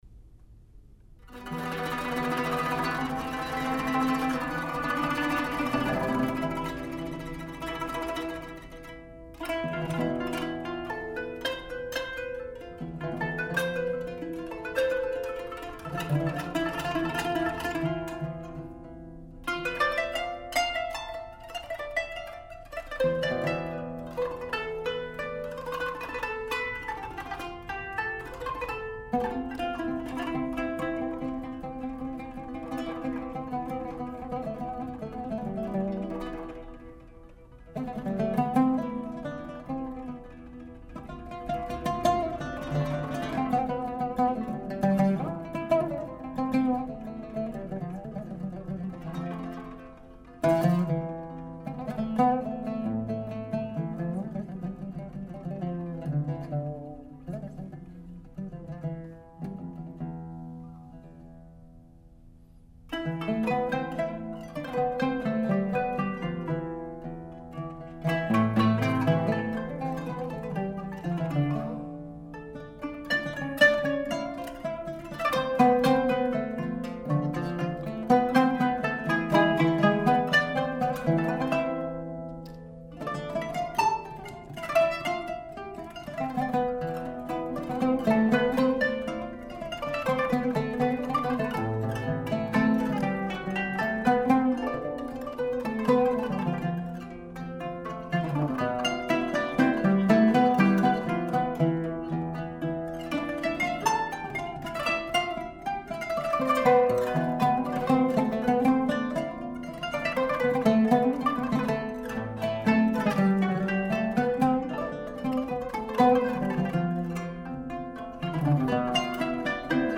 kanun
oud